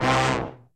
C2 POP FAL.wav